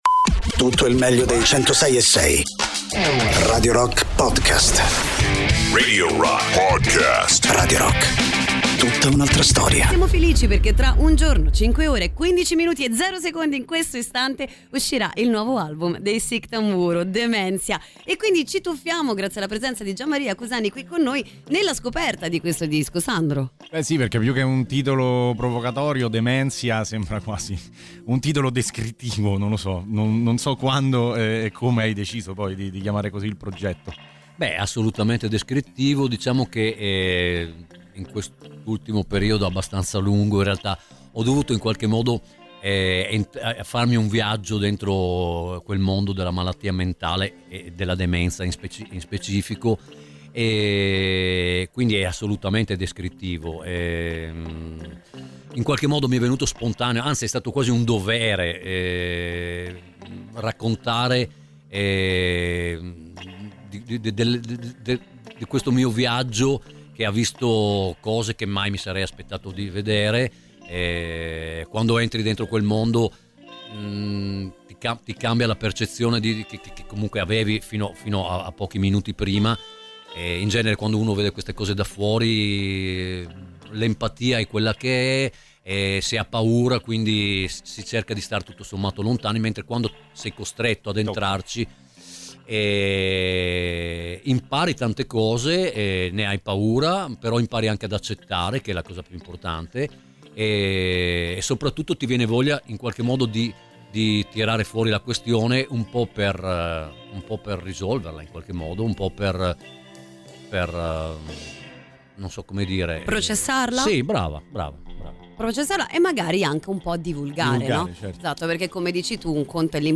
Intervista: Sick Tamburo (15-01-26)
ospite in studio